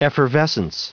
Prononciation du mot effervescence en anglais (fichier audio)
Prononciation du mot : effervescence